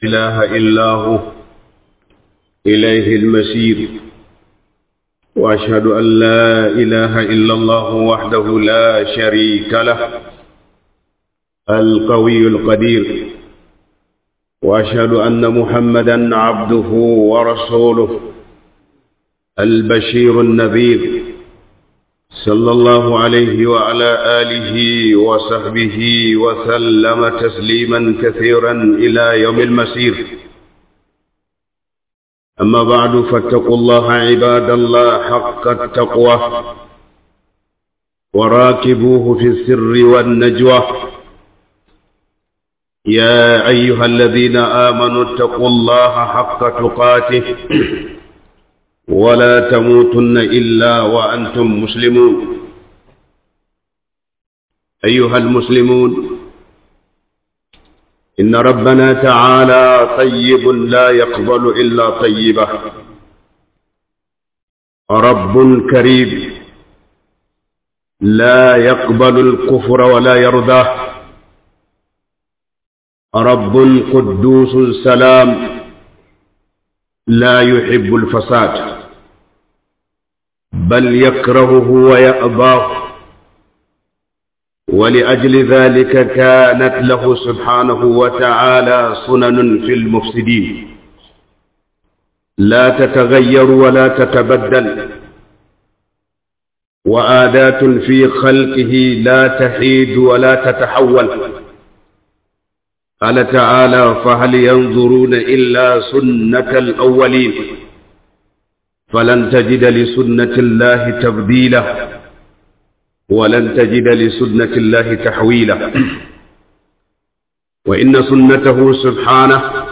LURA DA HALIN DA MUKE CIKI - 2025-12-05 - HUDUBA